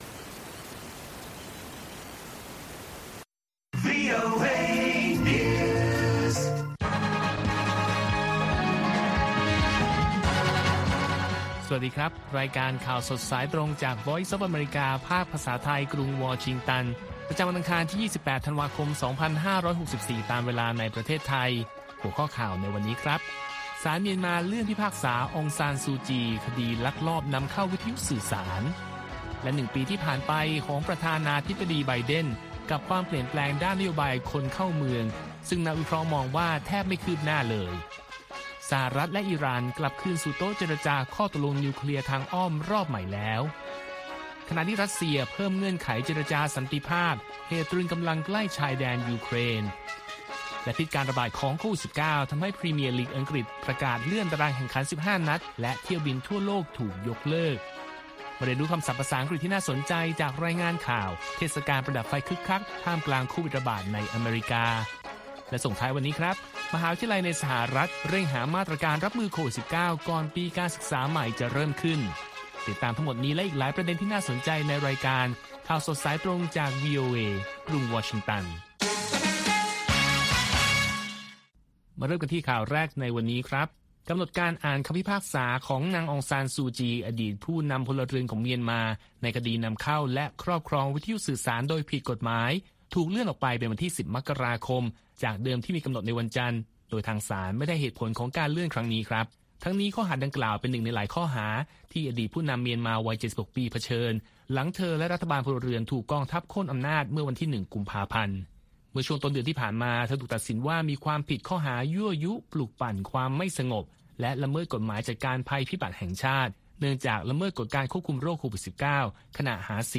ข่าวสดสายตรงจากวีโอเอ ภาคภาษาไทย ประจำวันอังคารที่ 28 ธันวาคม 2564 ตามเวลาประเทศไทย